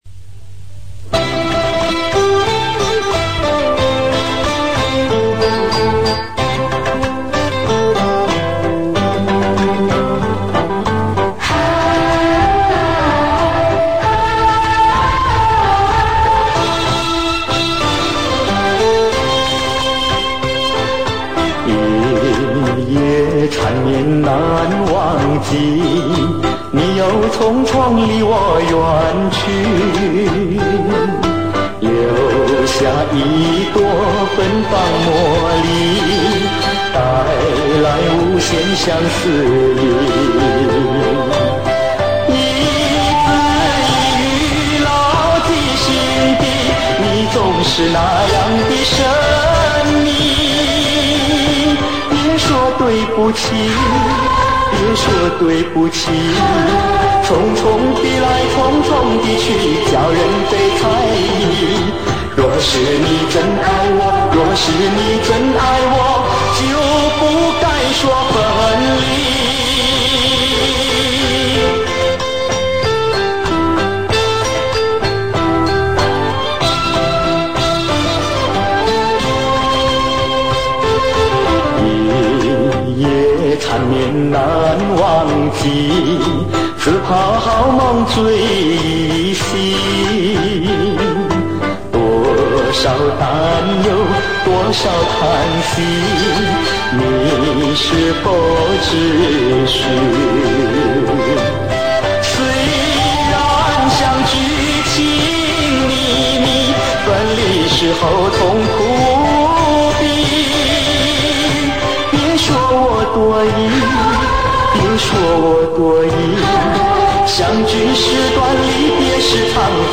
虽然本人的这首音质不好